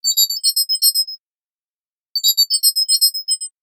07478 verification completed ding
access completed ding granted notification pass ring verification sound effect free sound royalty free Sound Effects